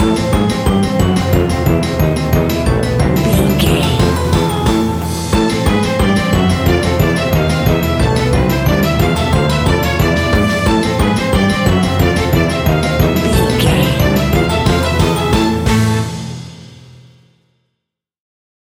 Aeolian/Minor
scary
ominous
eerie
strings
brass
synthesiser
percussion
piano
spooky
horror music